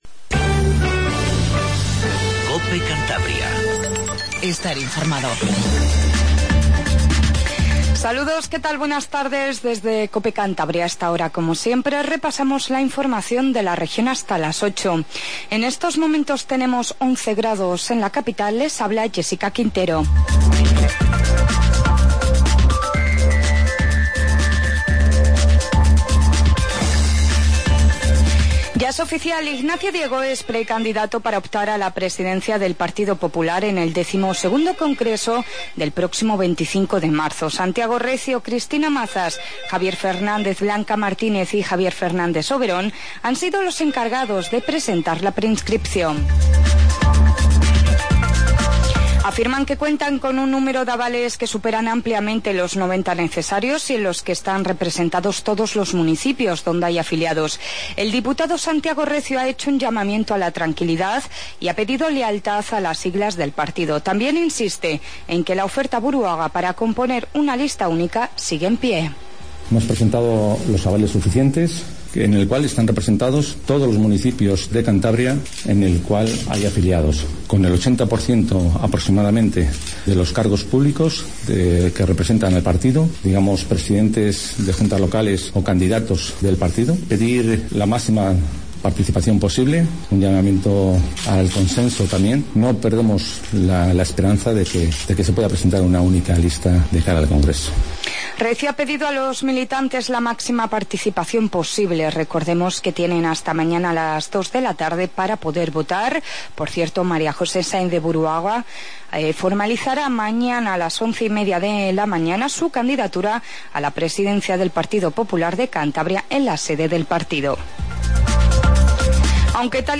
INFORMATIVO DE TARDE 19:50